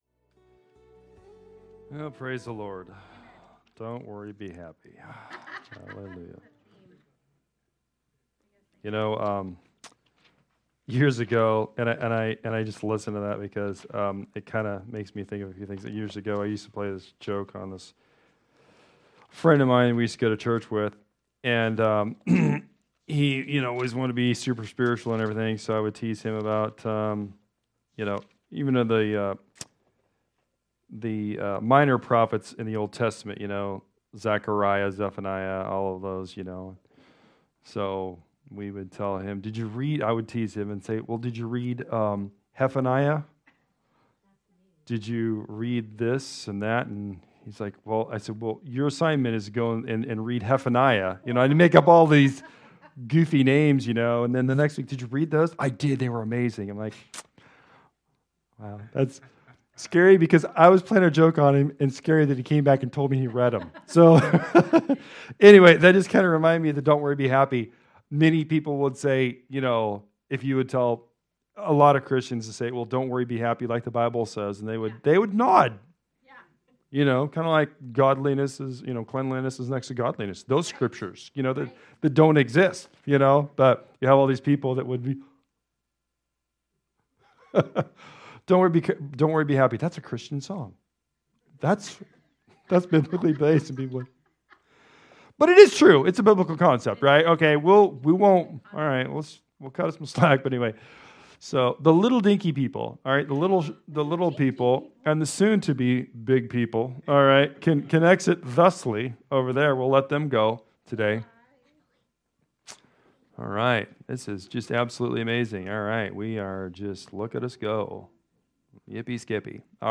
A collection of sermons/pastoral messages from 2018-2022.